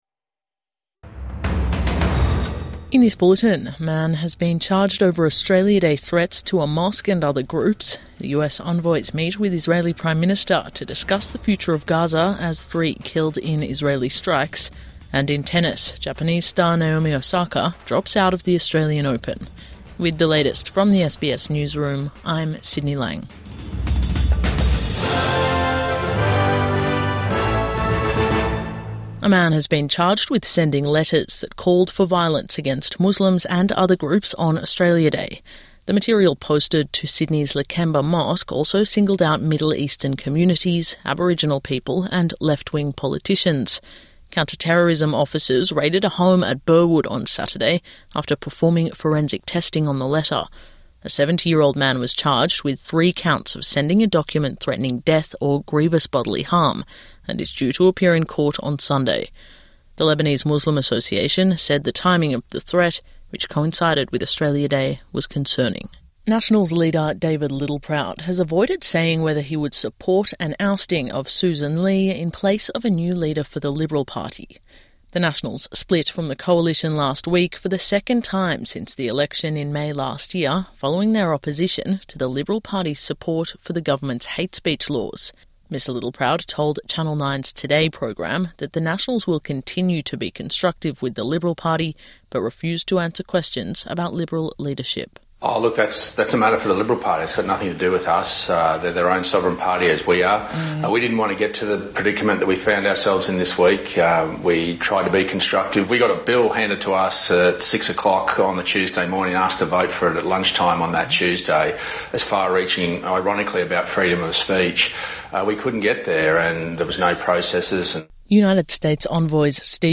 Man charged over Australia Day threats to mosque| Midday News Bulletin 25 January 2026